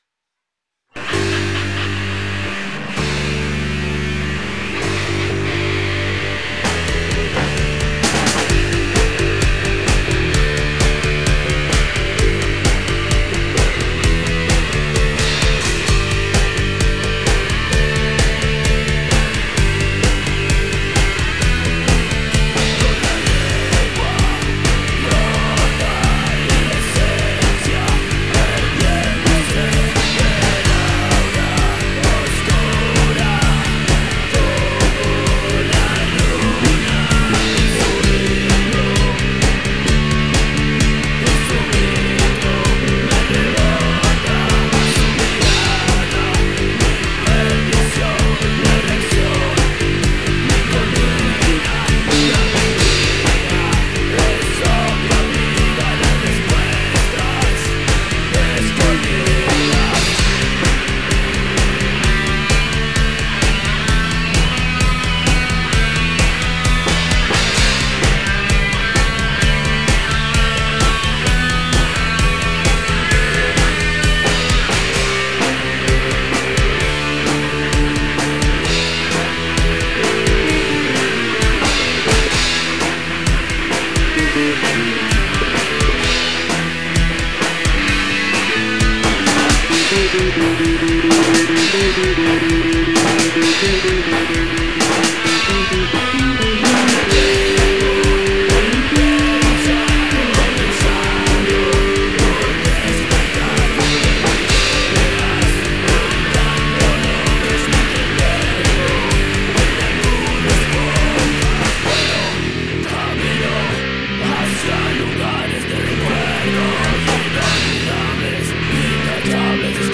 bajo
guitarra, voz
bateria, voz